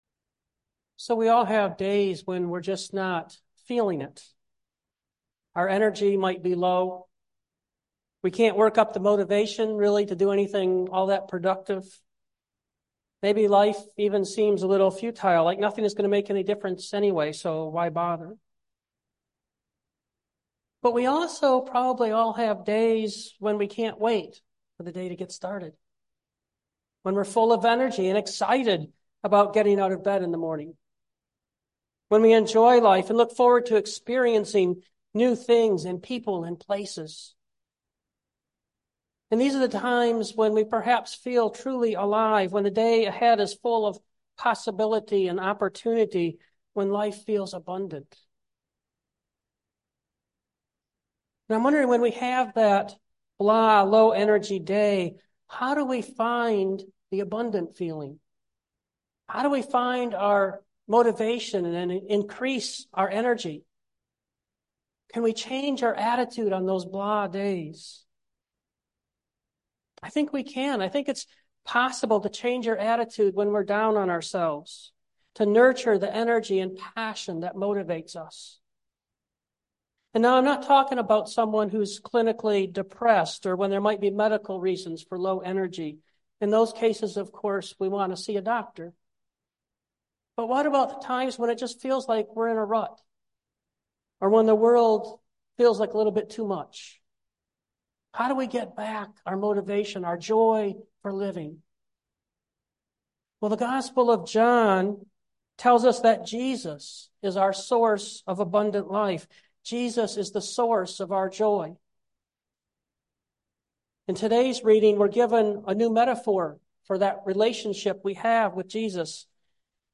A short imagination exercise follows the message.